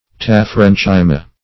Search Result for " taphrenchyma" : The Collaborative International Dictionary of English v.0.48: Taphrenchyma \Taph*ren"chy*ma\, n. [Gr.